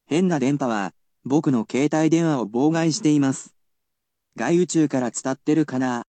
I will also tell you the pronunciation of the word using the latest in technological advancements.